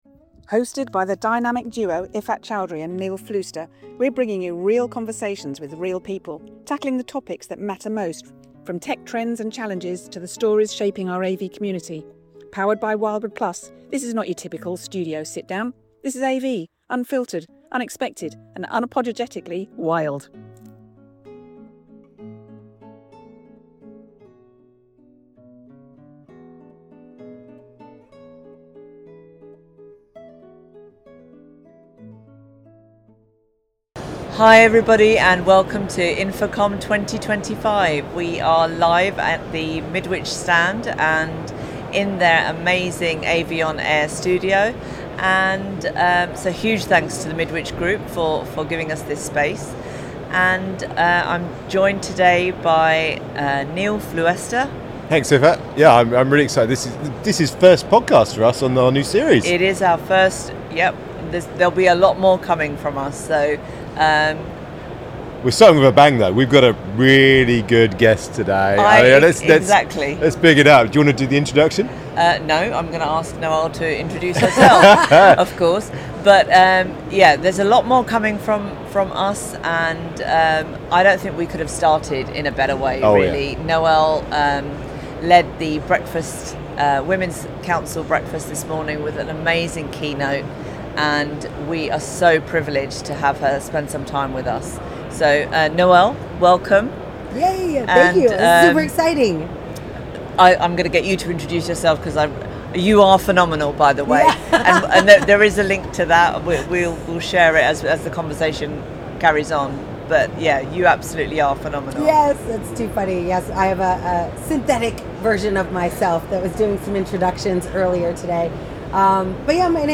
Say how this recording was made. Location: InfoComm 2025, Orlando, USA.